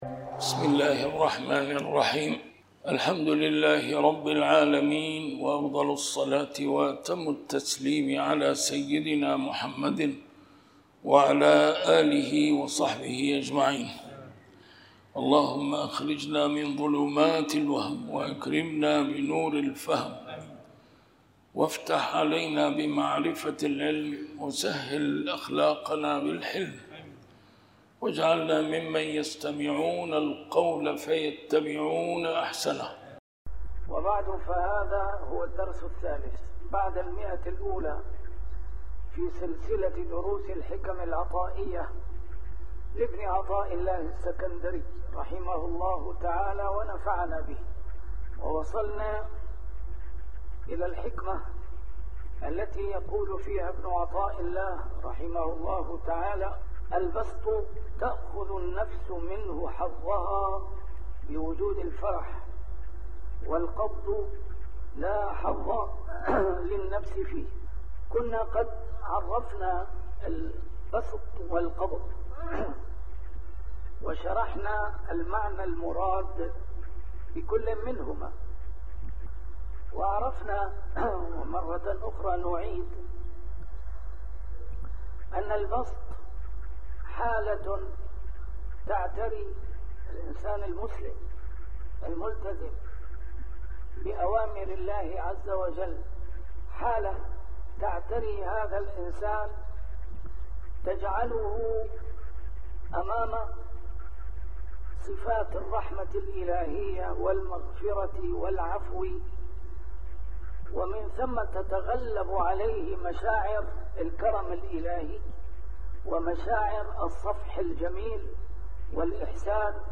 A MARTYR SCHOLAR: IMAM MUHAMMAD SAEED RAMADAN AL-BOUTI - الدروس العلمية - شرح الحكم العطائية - الدرس رقم 103 شرح الحكمة 82+83